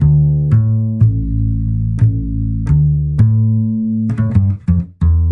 chillout " Jazz Bass B 2
描述：爵士乐，音乐，爵士乐